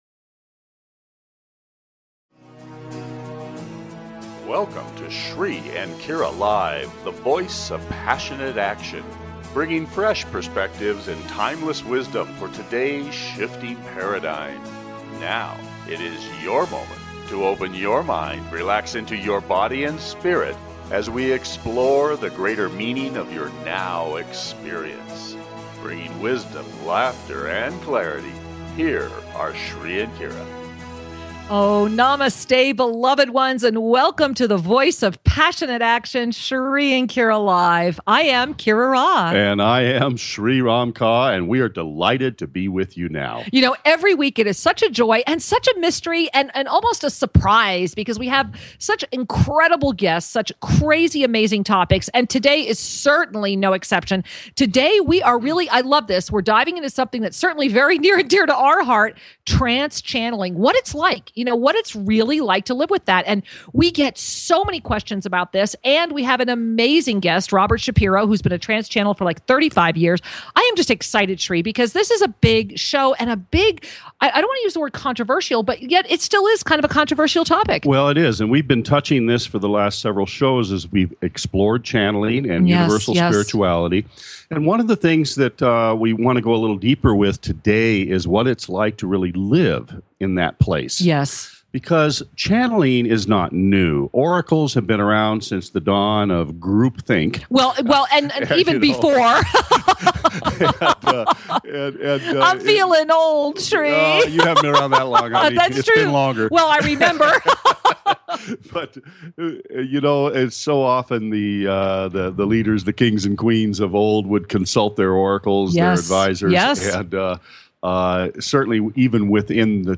Call in for mini soul readings, to ask questions or to comment.